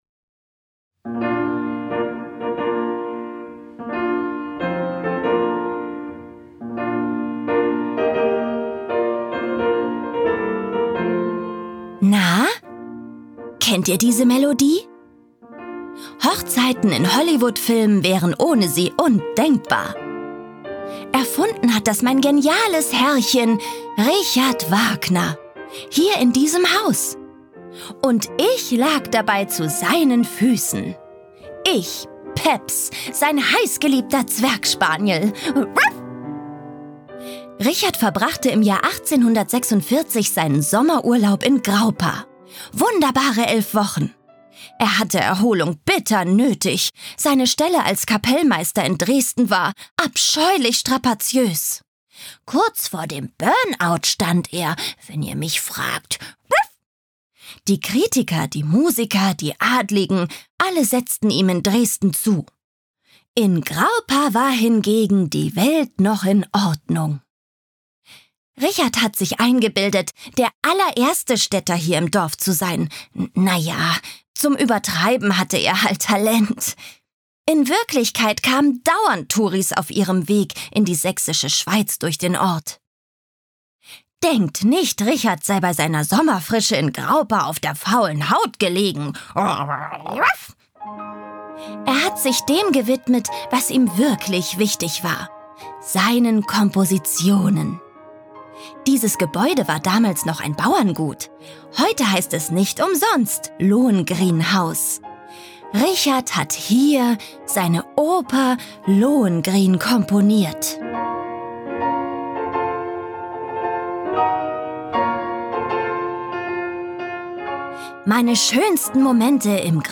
Erlebe die Richard-Wagner-Stätten in Graupa mit einem Audioguide und entdecke die prägenden Orte des Komponisten zusammen mit seiner Hündin Peps.